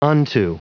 Prononciation du mot unto en anglais (fichier audio)
Prononciation du mot : unto